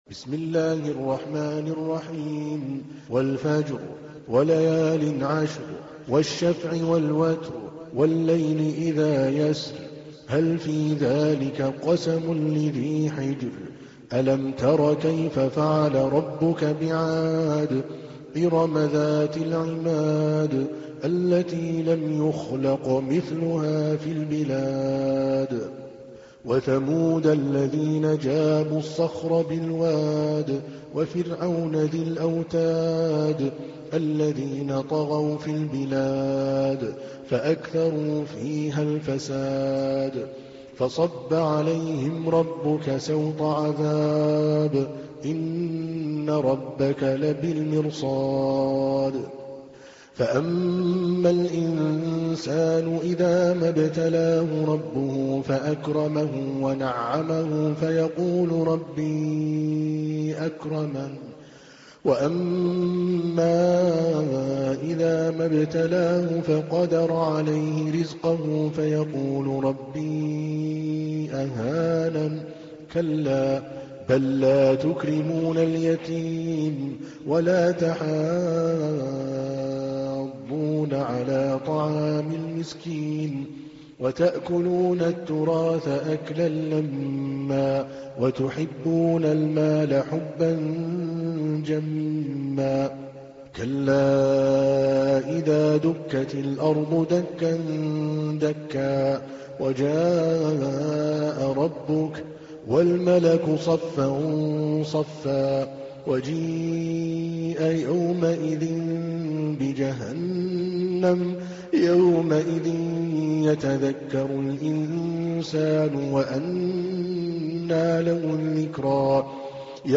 تحميل : 89. سورة الفجر / القارئ عادل الكلباني / القرآن الكريم / موقع يا حسين